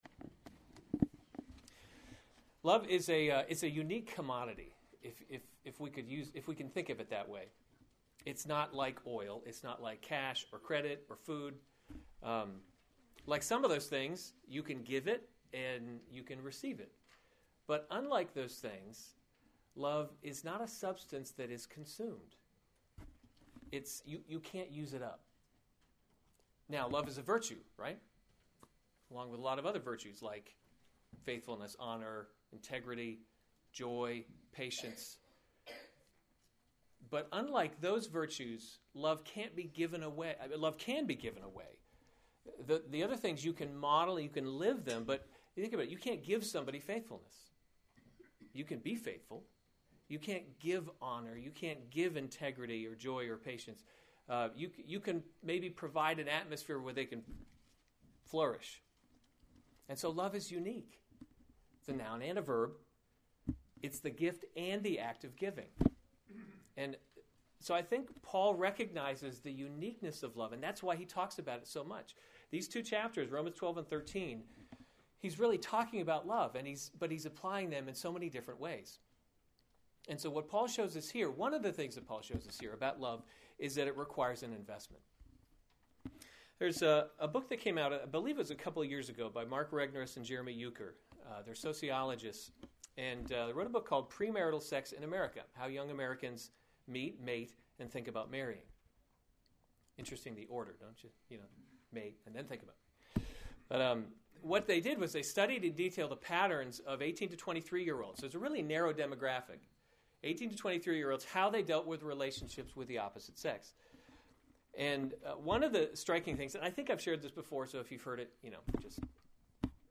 March 21, 2015 Romans – God’s Glory in Salvation series Weekly Sunday Service Save/Download this sermon Romans 13:8-10 Other sermons from Romans Fulfilling the Law Through Love 8 Owe no one […]